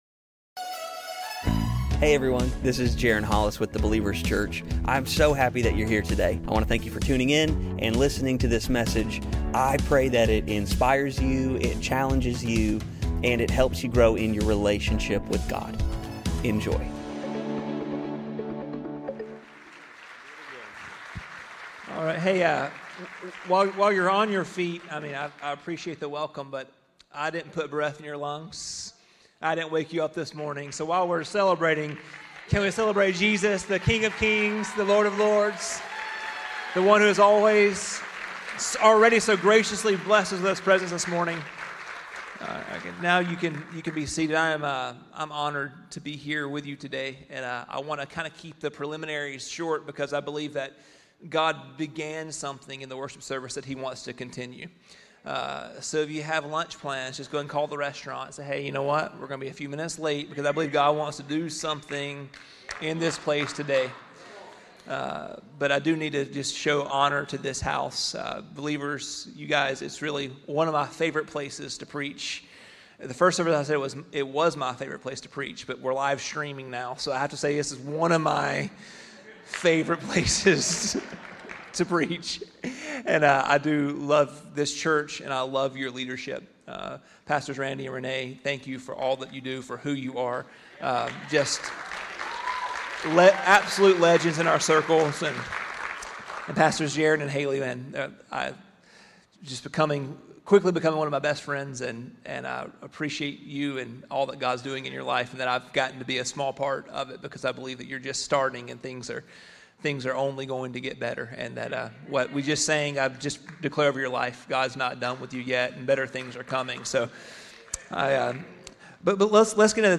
The Believers Church - Sunday Messages